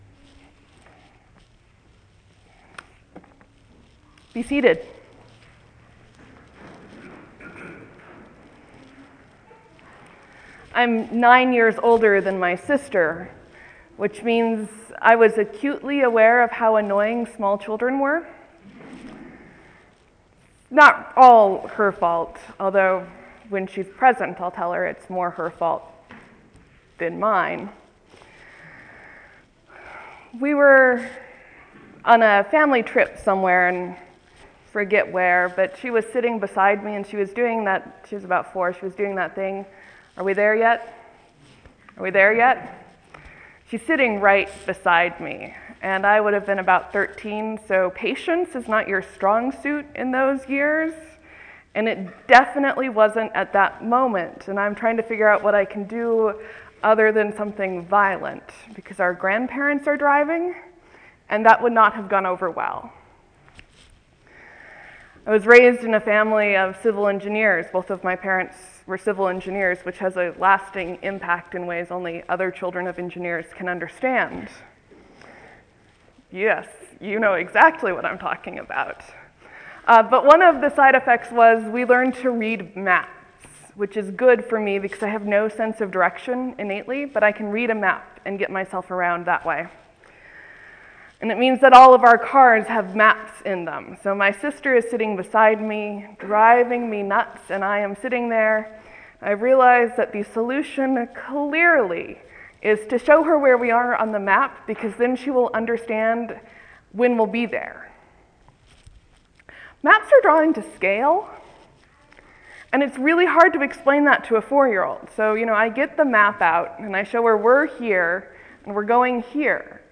Map to Halfway, Advent 1 sermon Dec 2013